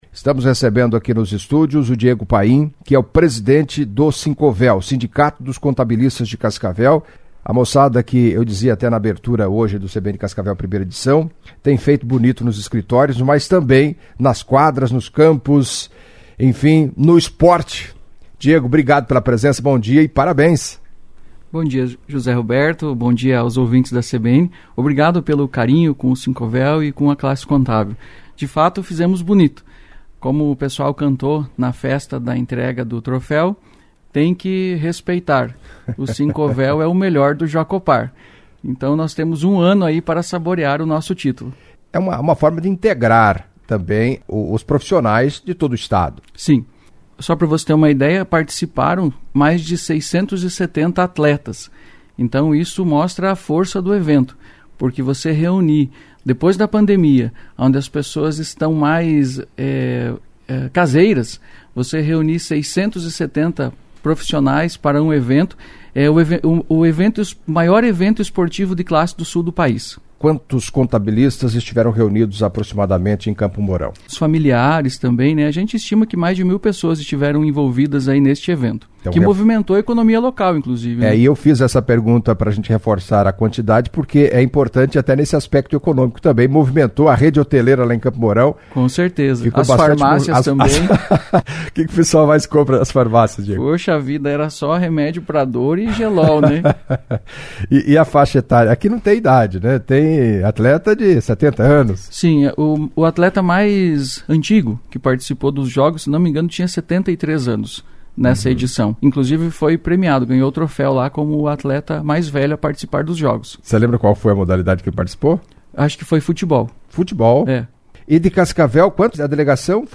Em entrevista à CBN Cascavel nesta terça-feira (15)